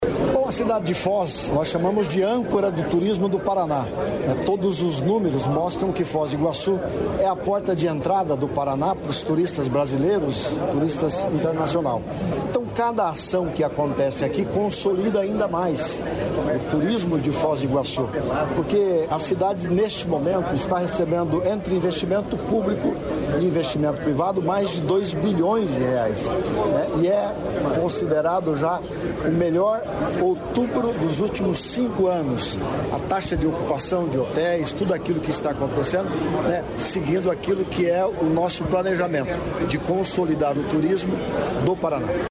Sonora do secretário de Turismo, Leonaldo Paranhos, sobre o novo aguário de Foz do Iguaçu